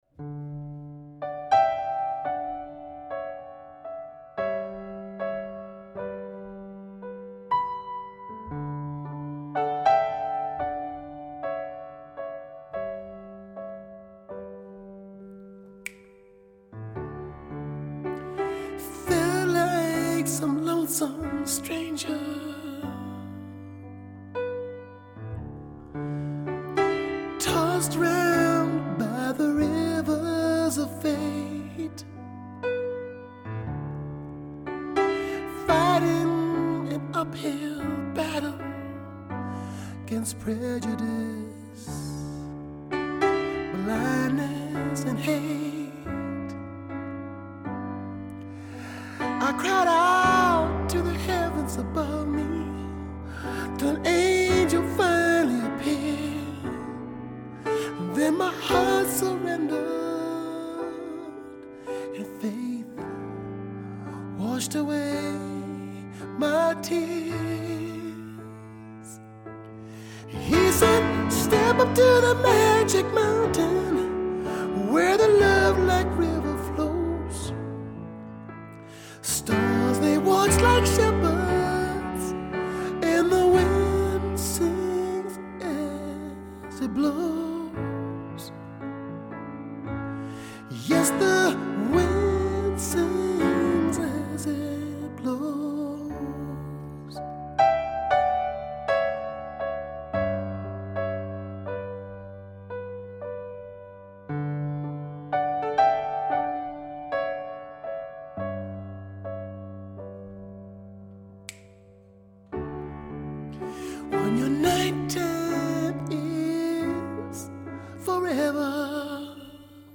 Driving ballad Ml vox, grand piano